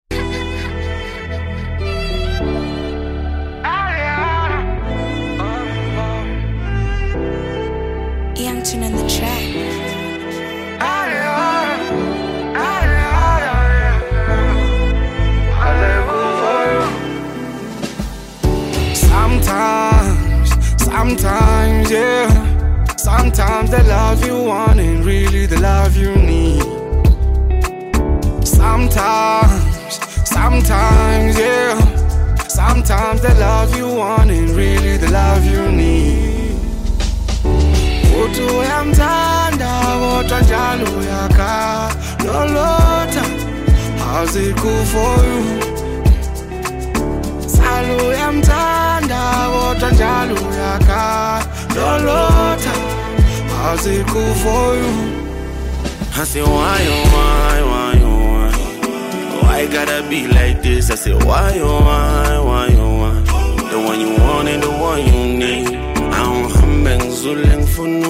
Home » Amapiano
South African singer-songsmith